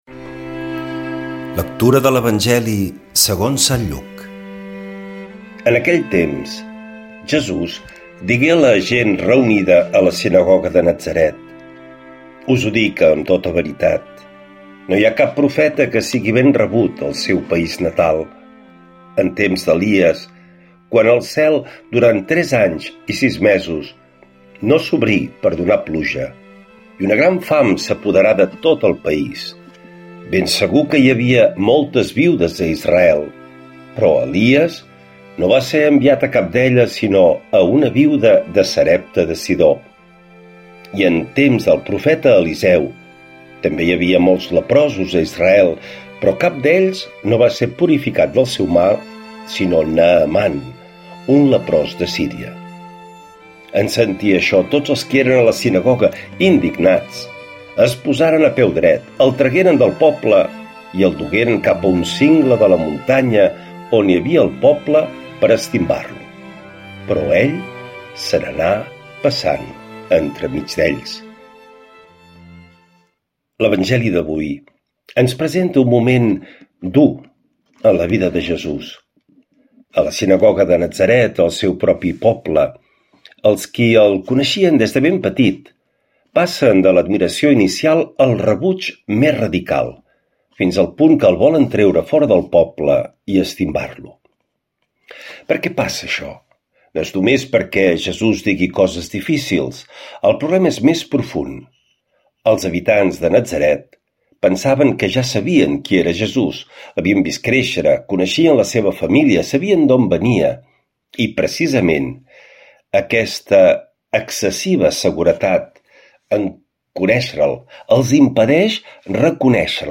L’Evangeli i el comentari de dilluns 09 març del 2026.
Lectura de l’evangeli segons sant Lluc